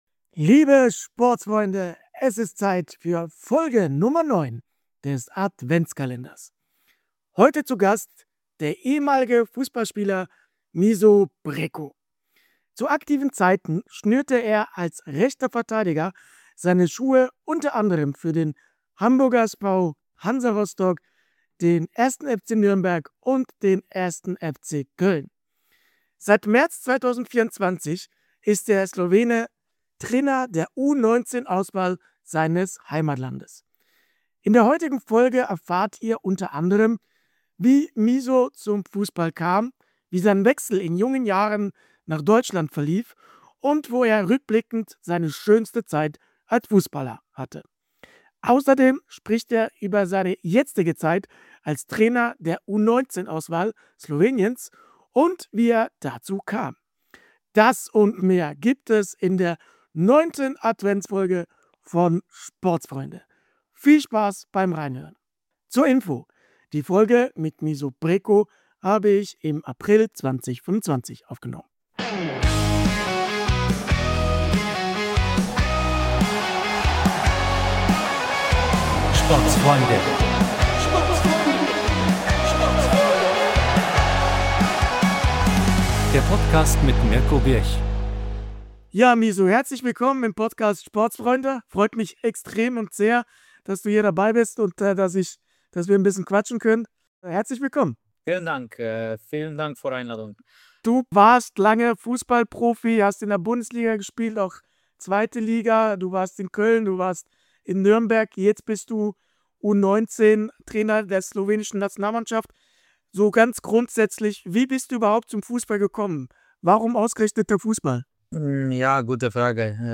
Zu Gast ist der ehemalige Profifussballer Mišo Brečko.